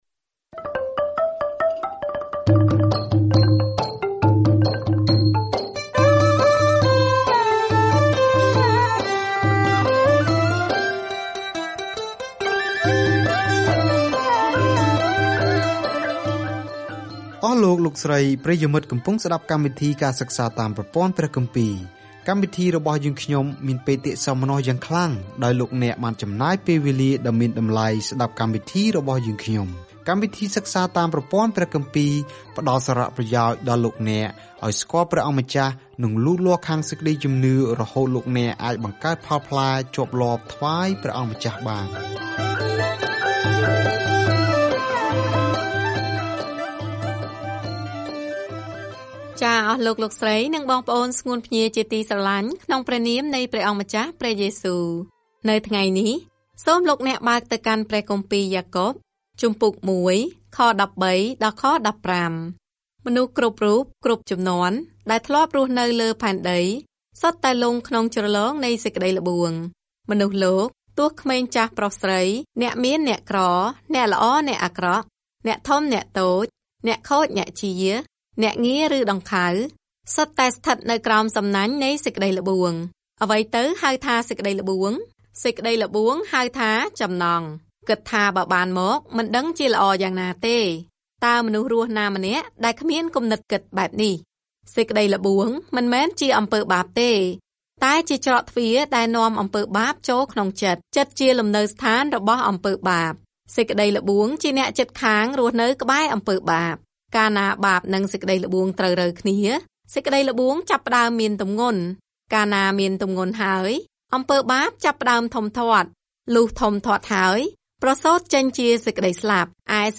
ដាក់ជំនឿរបស់អ្នកទៅក្នុងសកម្មភាព។ ការធ្វើដំណើរជារៀងរាល់ថ្ងៃតាមរយៈយ៉ាកុប នៅពេលអ្នកស្តាប់ការសិក្សាជាសំឡេង ហើយអានខគម្ពីរដែលជ្រើសរើសពីព្រះបន្ទូលរបស់ព្រះ។